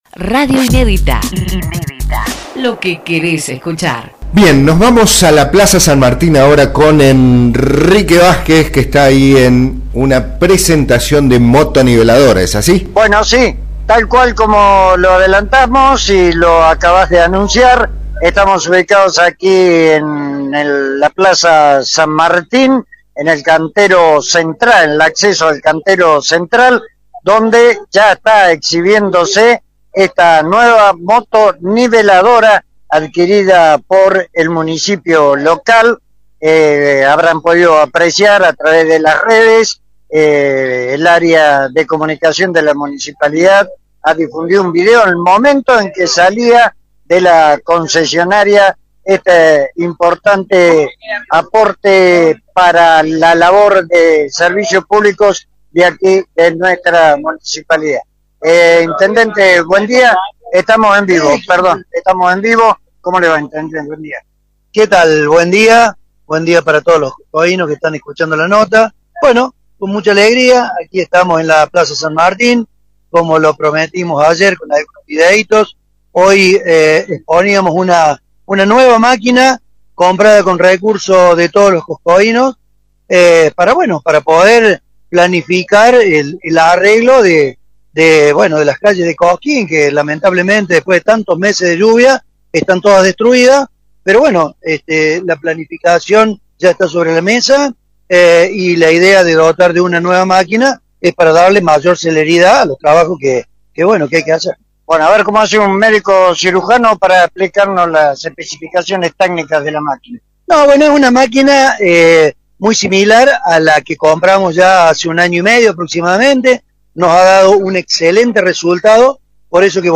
En una presentación realizada en la Plaza San Martín, el municipio de Cosquín incorporó una nueva motoniveladora destinada a mejorar el estado de las calles de tierra en distintos barrios de la ciudad.
Movil-Presentacion-Moto-Niveladora-21-04.mp3